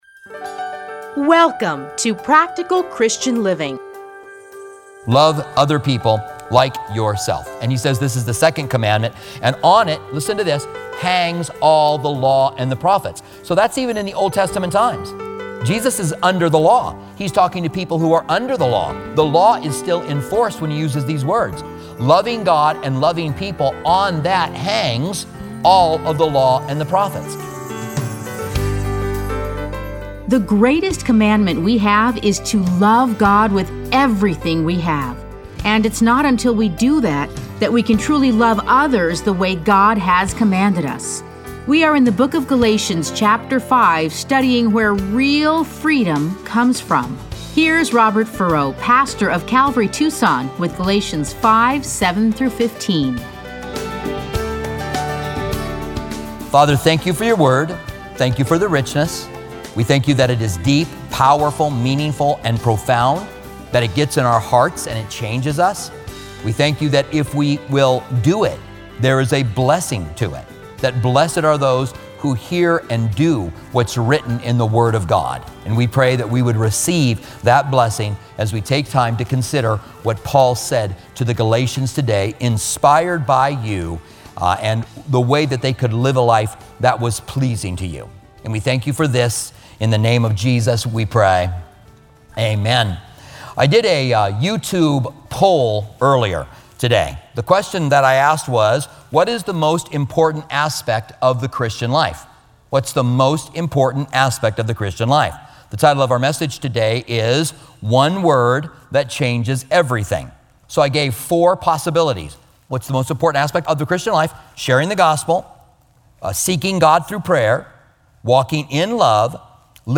Listen to a teaching from Galatians 5:7-15.